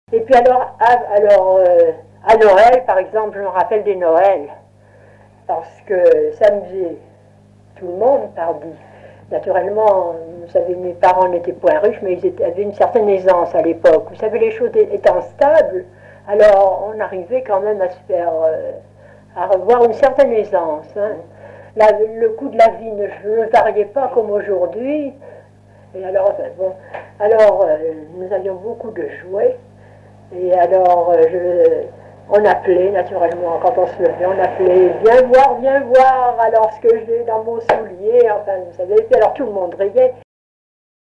Collectif chansons, témoignages
Catégorie Témoignage